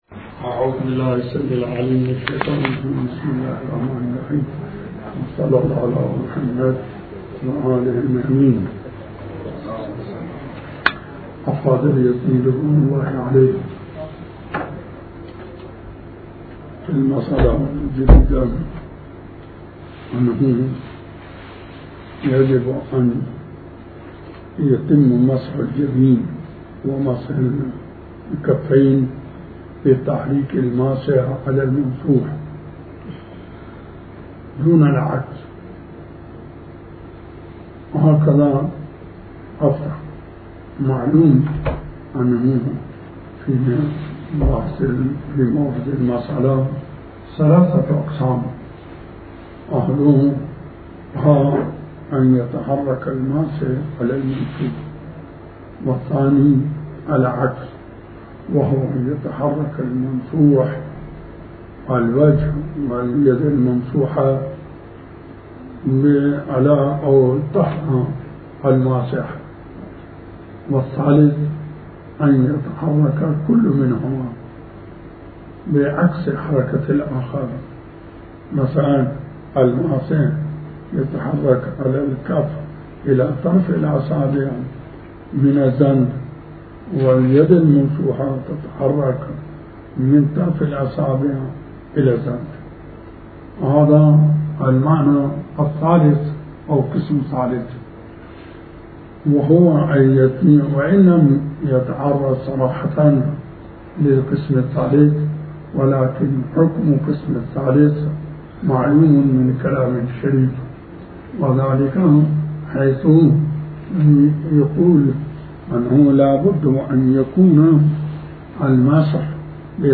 تحمیل آیةالله الشيخ بشير النجفي بحث الفقه 38/04/01 بسم الله الرحمن الرحيم الموضوع : التيمم _ شرائط التيمم - افاد السيد اليزدي انه يجب مسح الجبين والكفين بتحرك الماسح على الممسوح دون العكس .